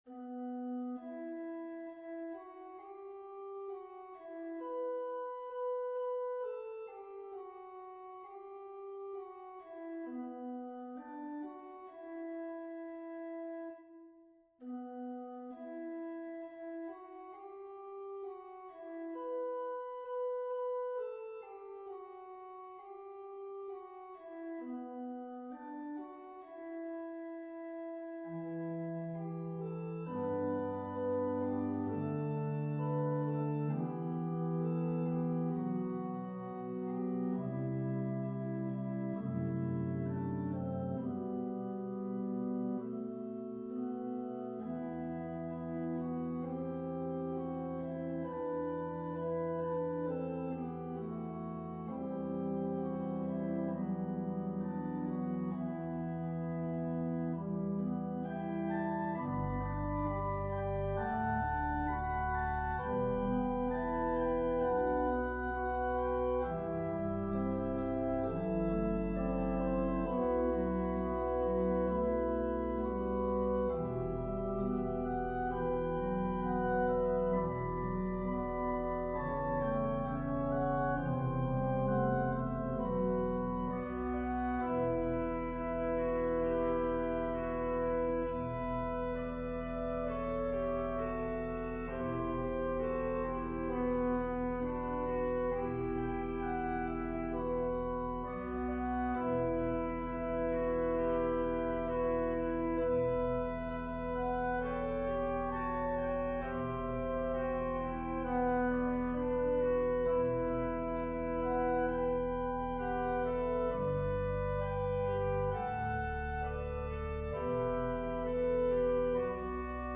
An organ solo version